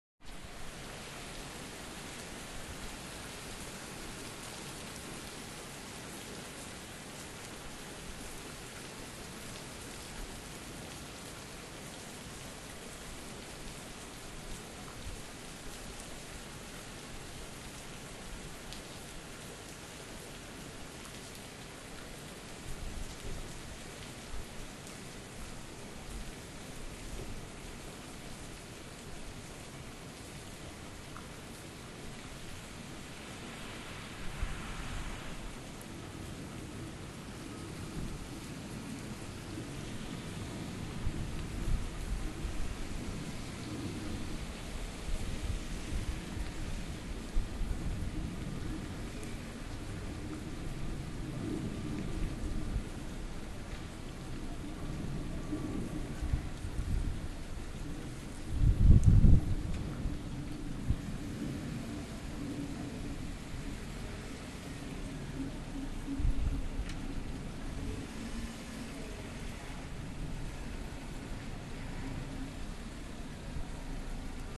Urban monsoon
Elsewhere, I've never experienced such intense, warm rainfalls which turn the streets into little rivers, the urban scenario into biblical floods. This is a rainy Atlantic afternoon from my window.